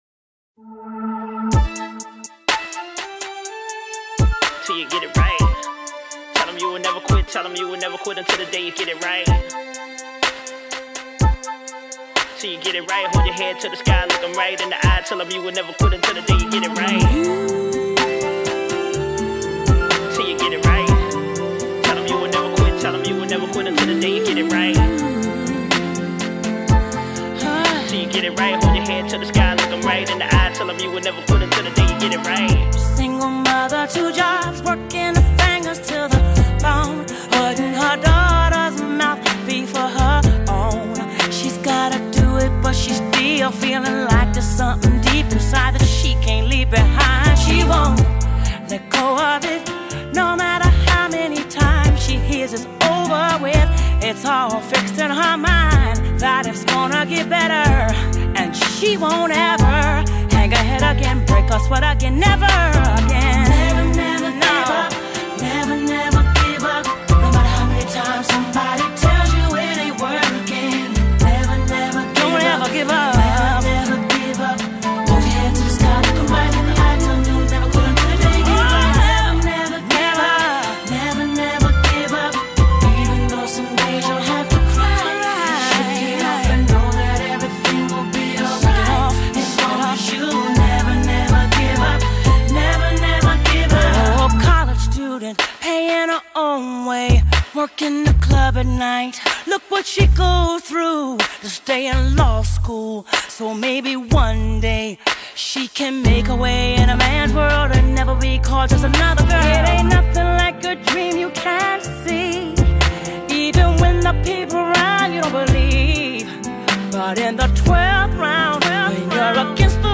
stylish slice of uplifting R&B